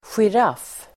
Ladda ner uttalet
giraff substantiv, giraffe Uttal: [sjir'af:] Böjningar: giraffen, giraffer Definition: fläckigt djur med lång hals av släktet Giraffa (spotted, long-necked animal of the genus Giraffa) giraffe substantiv, giraff